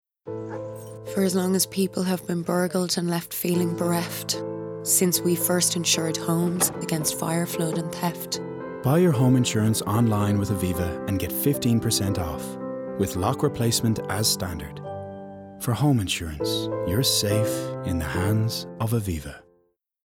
20/30's Irish, Natural/Compelling/Authentic
Commercial Showreel
Aviva Voice Over